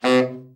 TENOR SN   5.wav